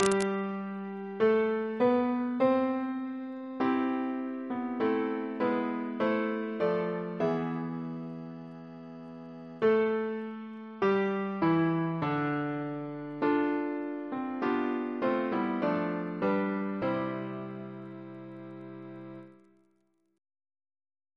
CCP: Chant sampler
Double chant in C Composer: Thomas Tertius Noble (1867-1953) Reference psalters: ACP: 102